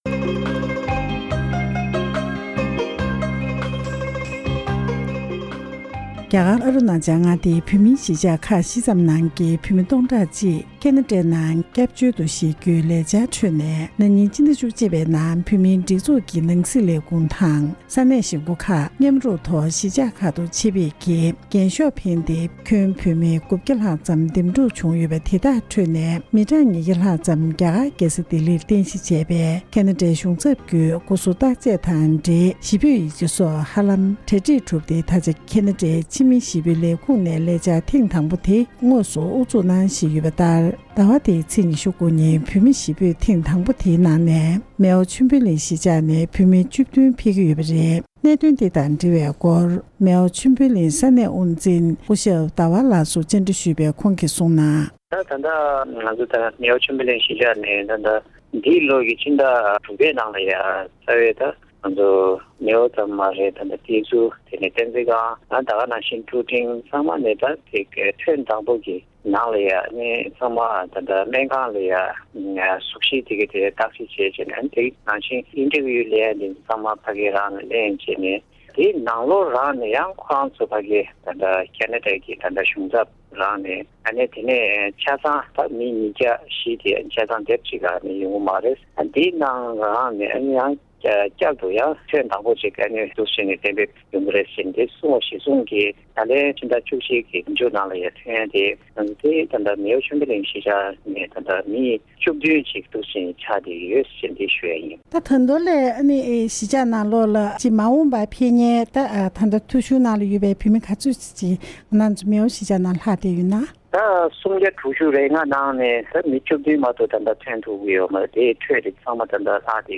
འབྲེལ་ཡོད་མི་སྣར་གནས་འདྲི་ཞུས